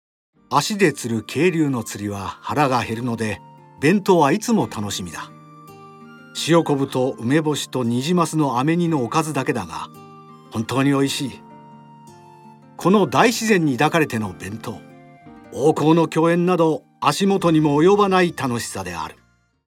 ボイスサンプル
ナレーション